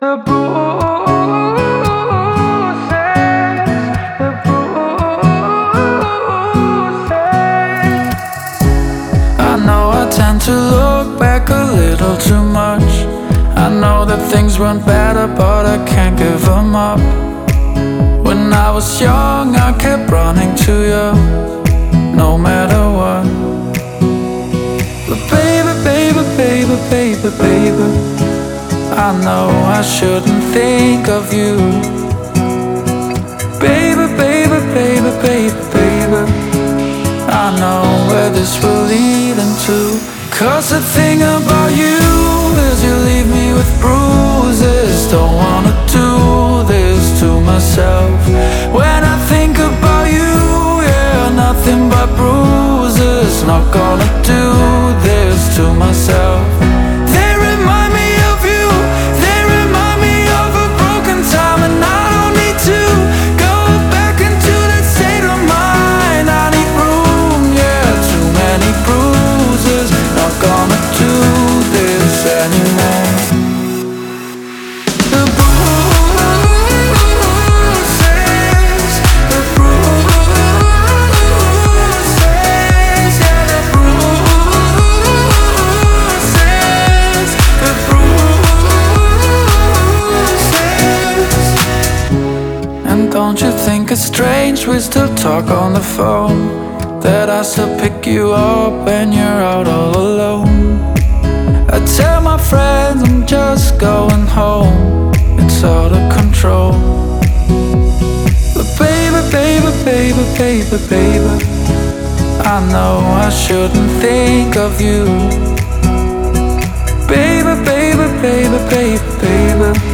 это трек в жанре электронной музыки